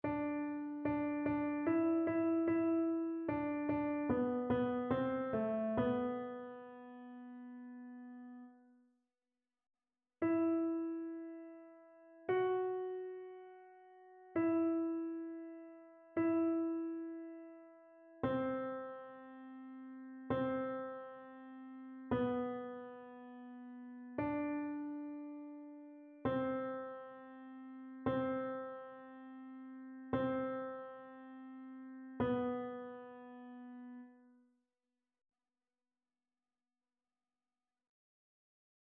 TénorBasse